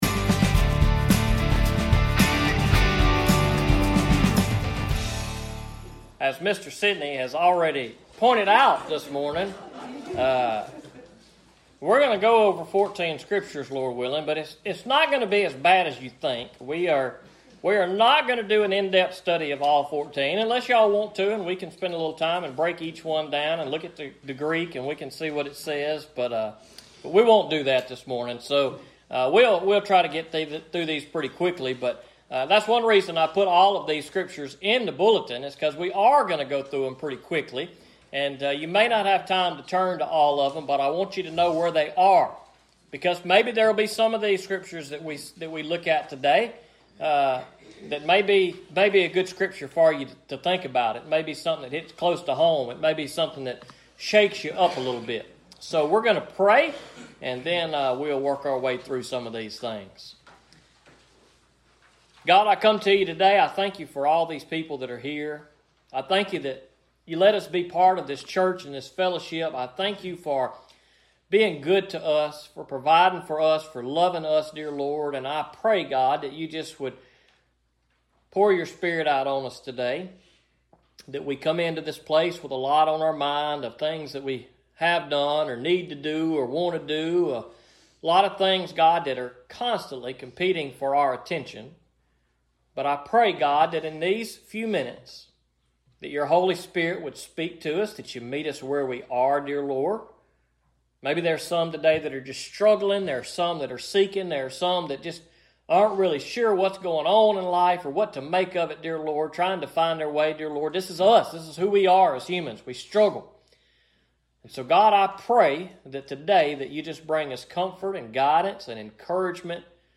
Sermons preached at Enterprise Baptist Church in Liberty MS